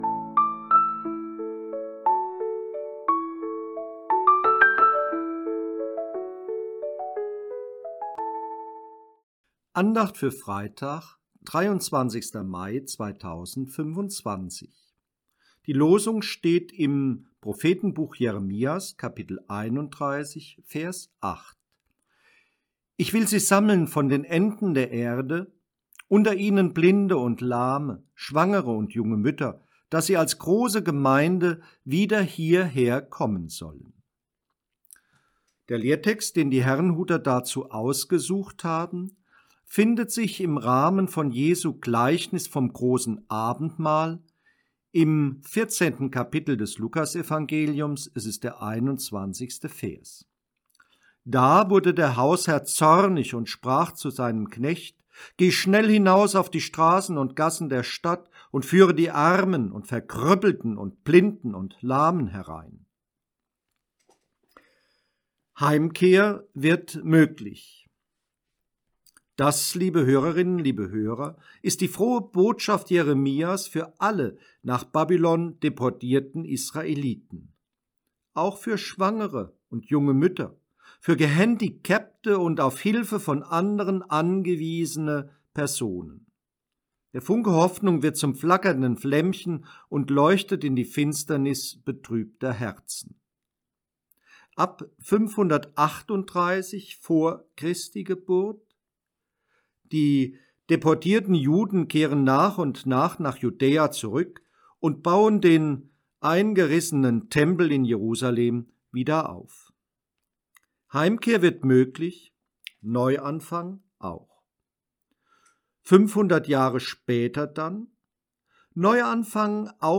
Losungsandacht für Freitag, 23.05.2025